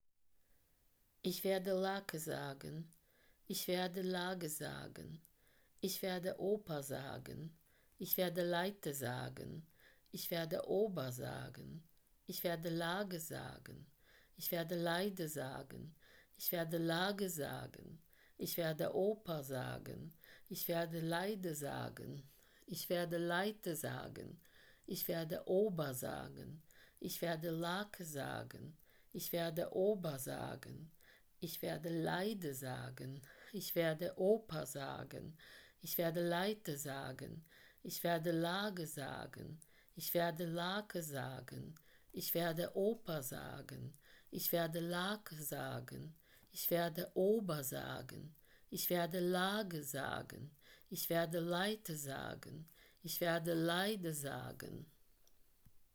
Plosive_BG.wav